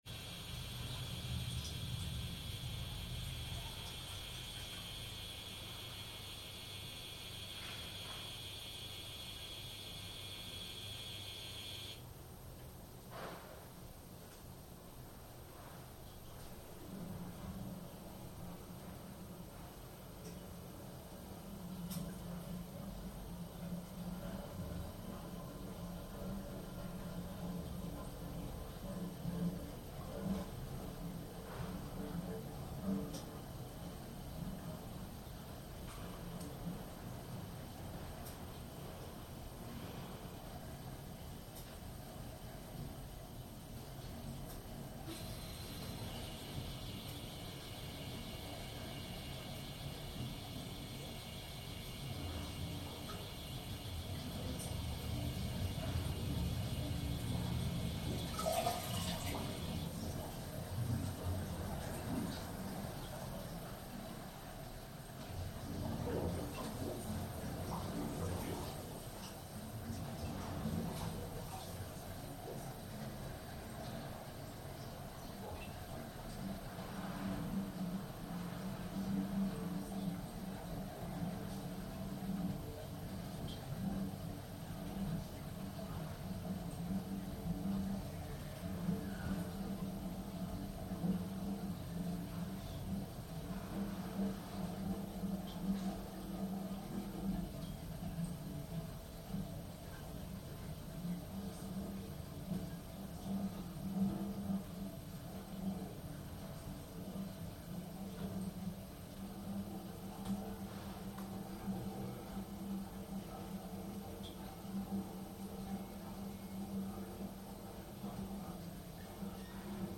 Public bath cleaning, Tokyo
This sound was recorded in 2025 at Sengoku-yu, where there is a public bath.
This was recorded in front of the boiler, and behind the high pressure washer. The sound come from these machines.
At the beginning of the recording, the boiler machine is starting to work. After that, three people come back from a smoking break and start working again with the high pressure washer.